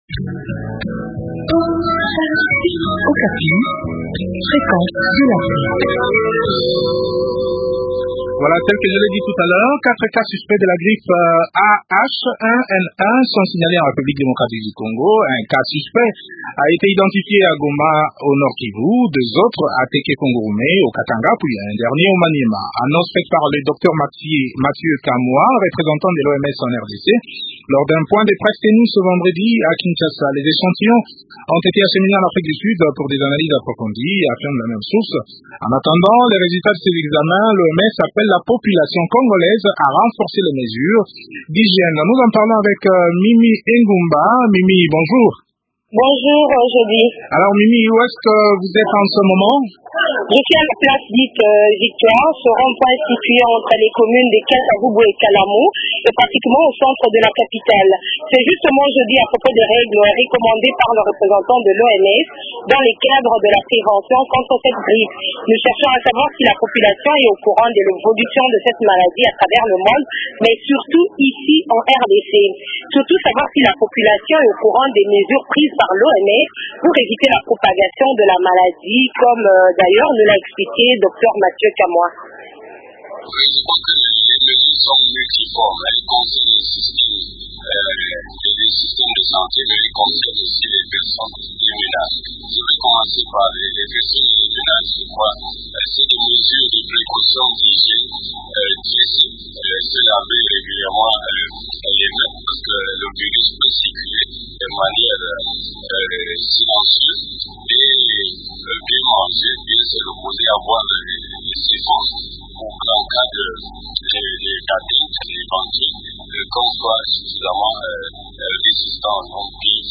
en parle avec Augustin Mopipi, ministre national de la santé publique.